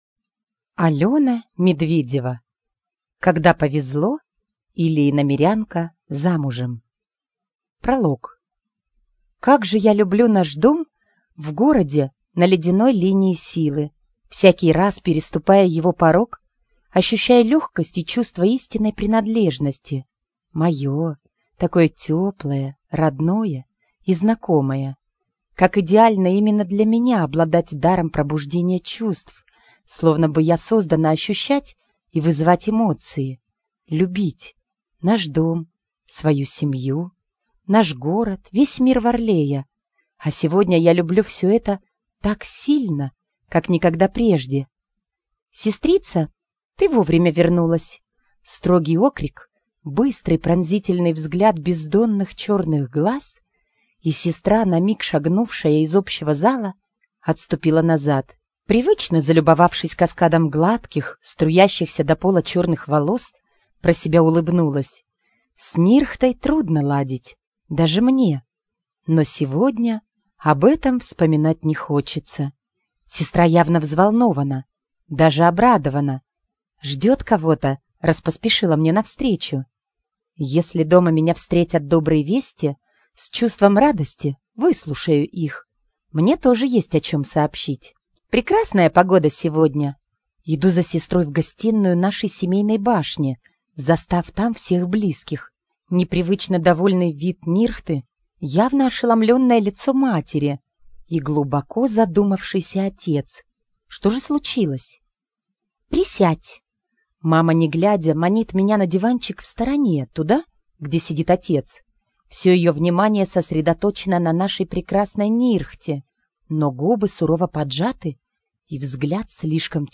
Аудиокнига Когда повезло, или Иномирянка замужем - купить, скачать и слушать онлайн | КнигоПоиск